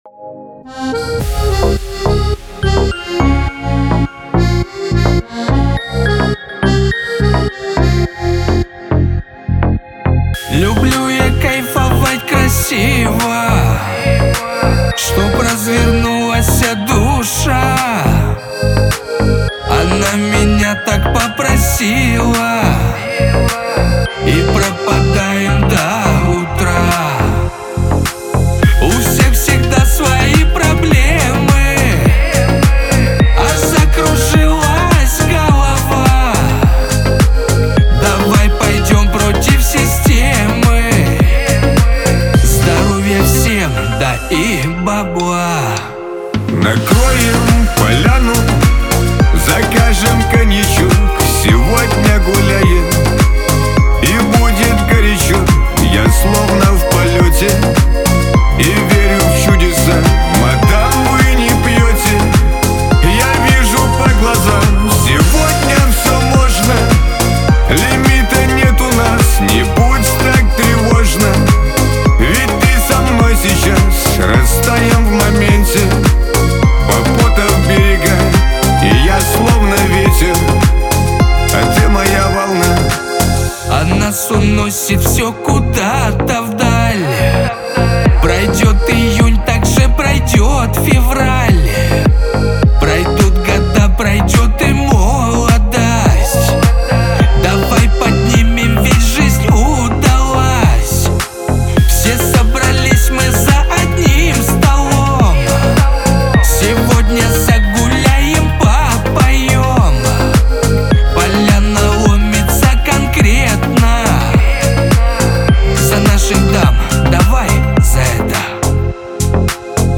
дуэт
Лирика
Шансон